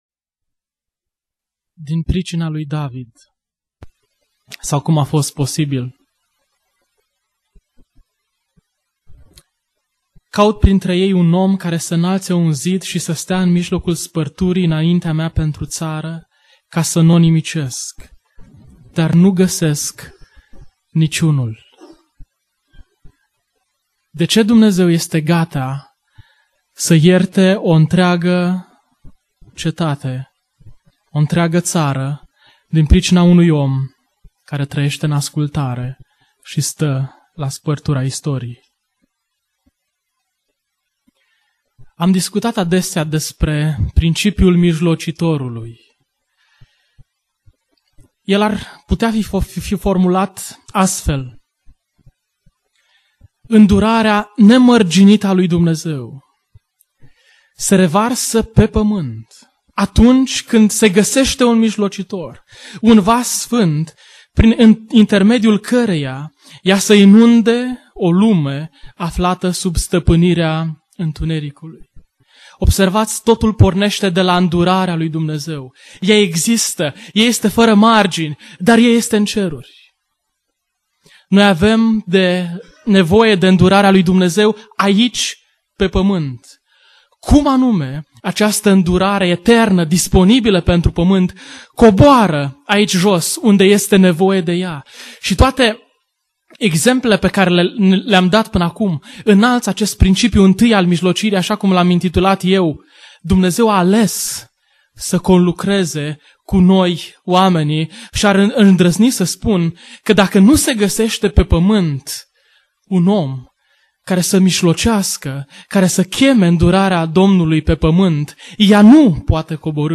Conferinta David om dupa inima lui Dumnezeu-vineri-sesiunea2